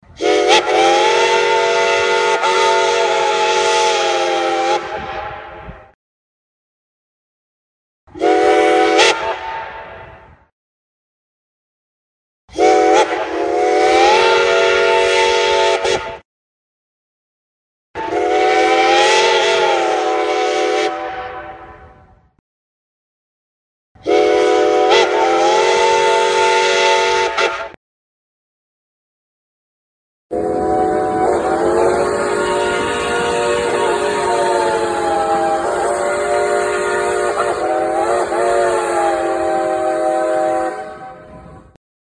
Recorded Whistles for Live Steam Locomotives
Nathan 6 Chime
whistles_6_chime.mp3